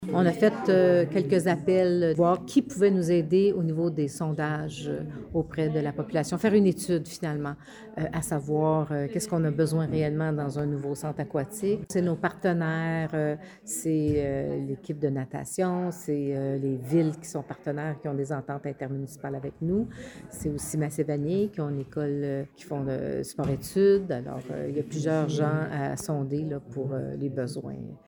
Nouvelles
La mairesse de Cowansville, Sylvie Beauregard, explique que plusieurs choses sont à prendre en compte pour élaborer le projet: